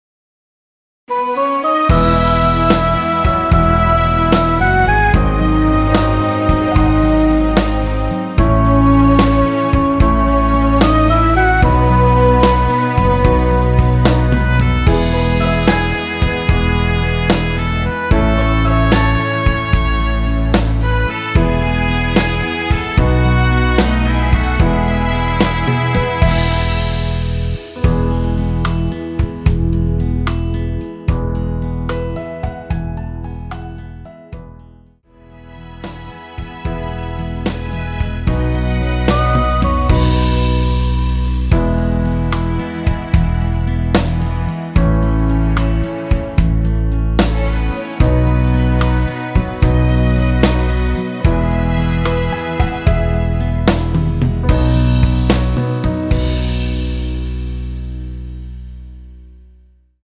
원키에서(-2)내린 (1절+엔딩)으로 진행되는 MR입니다.
앞부분30초, 뒷부분30초씩 편집해서 올려 드리고 있습니다.